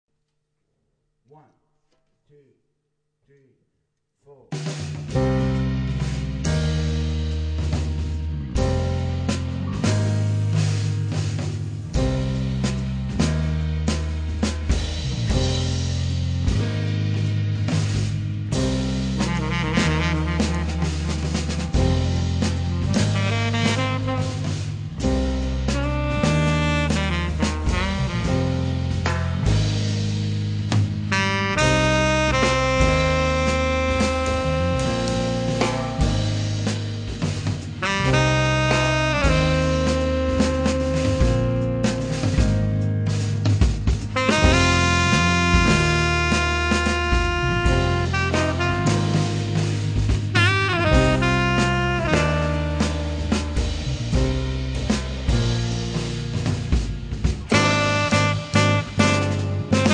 sax tenore
pianoforte
basso
batteria